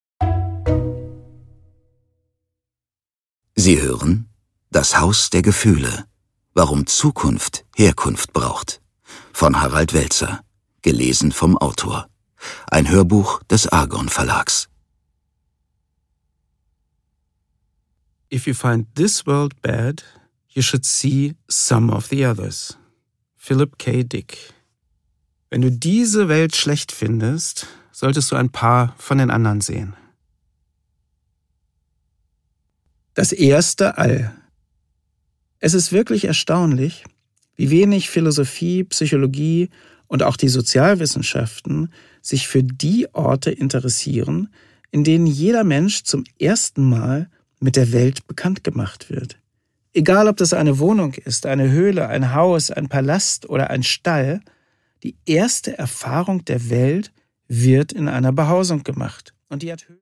Produkttyp: Hörbuch-Download
Gelesen von: Prof. Dr. Harald Welzer